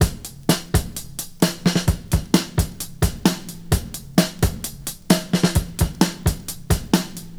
• 130 Bpm Drum Loop B Key.wav
Free breakbeat - kick tuned to the B note. Loudest frequency: 1954Hz
130-bpm-drum-loop-b-key-cXS.wav